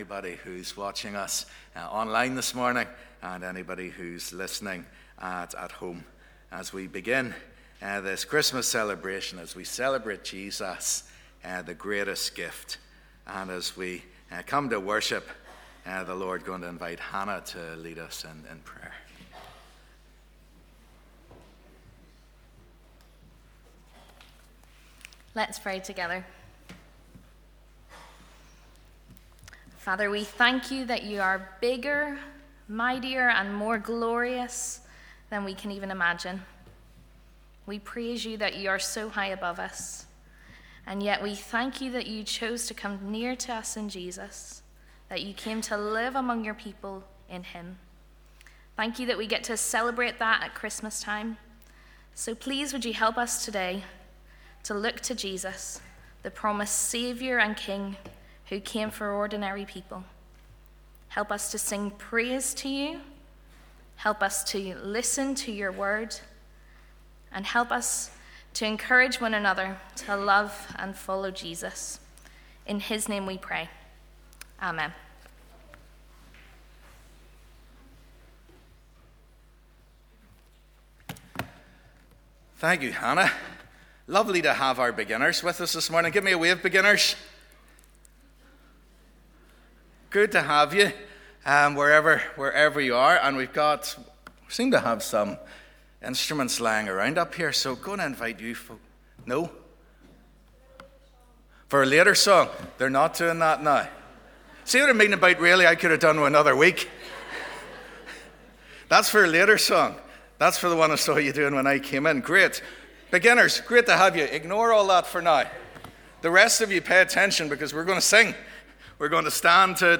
Welcome to our annual Christmas Gift Family Service. Today we begin to think about our theme for this Christmas - 'Jesus - The Greatest Gift' Thank you to all who are taking part in our service today.
Live @ 10:30am Morning Service